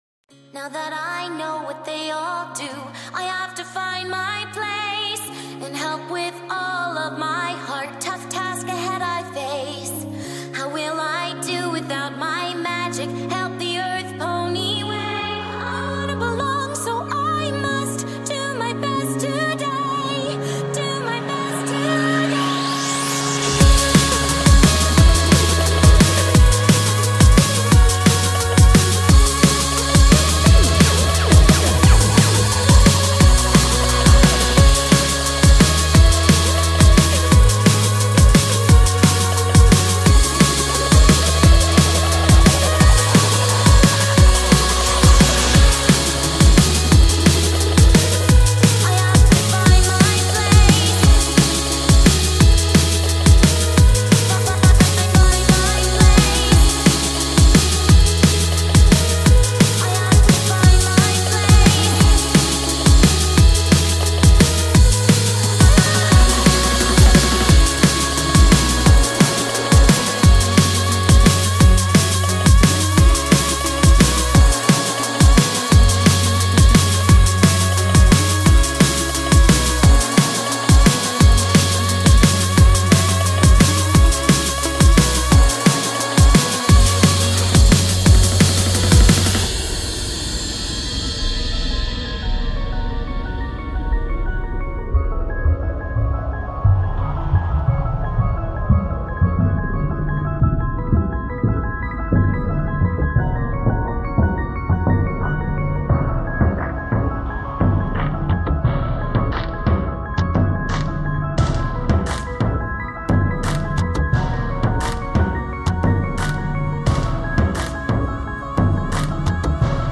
My first D&B/Dubstep track!